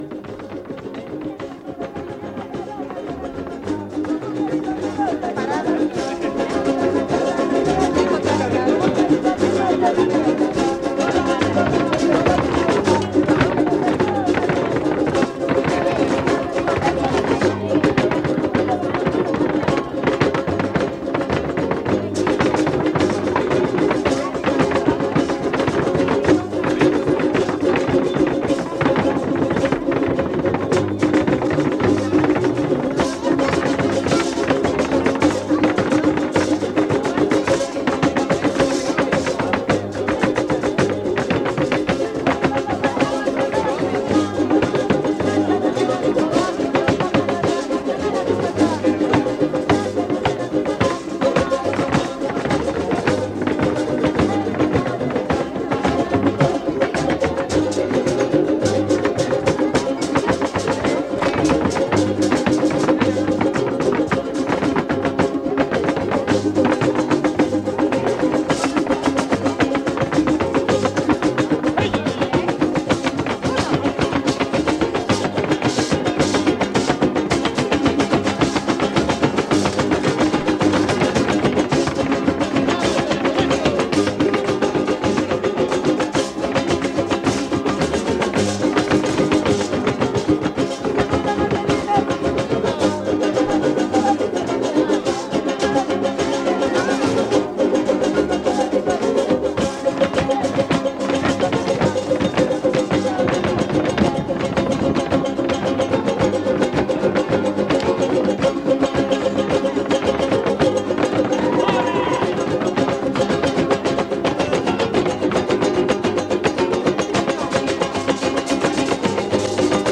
Encuentro de son y huapango